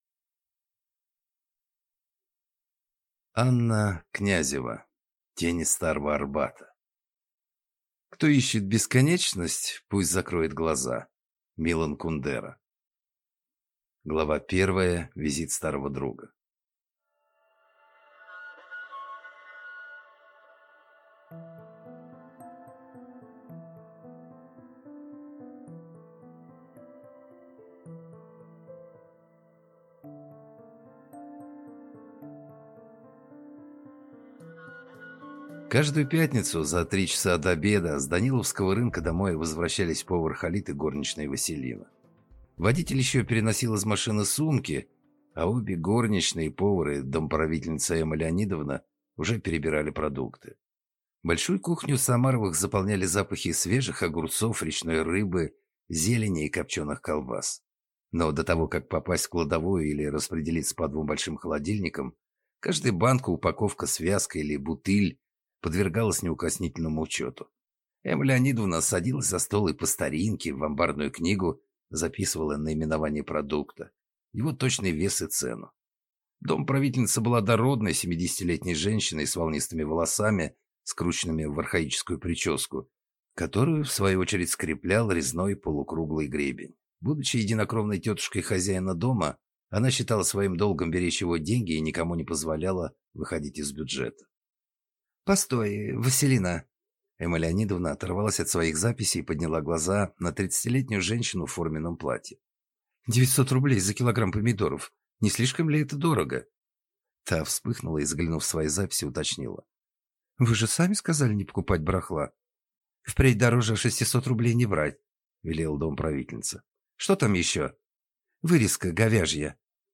Аудиокнига Тени Старого Арбата | Библиотека аудиокниг